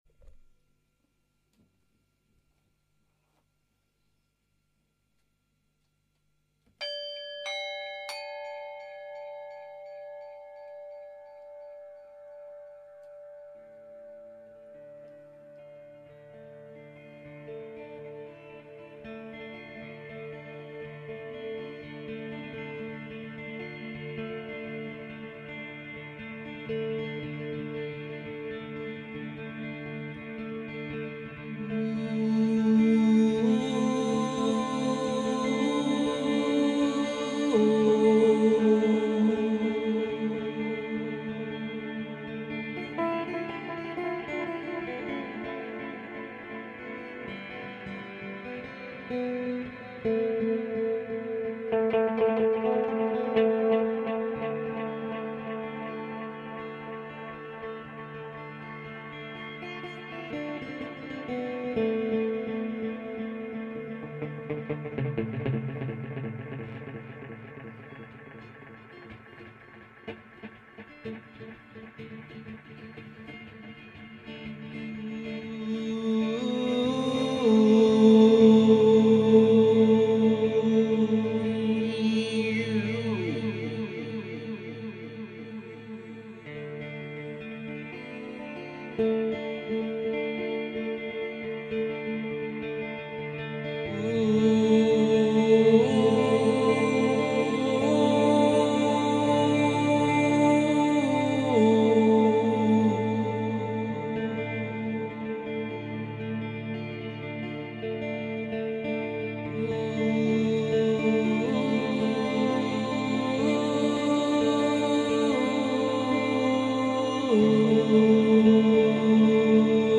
HANG & DUB 2016
a hangplayer
hang-dub-1-28Us1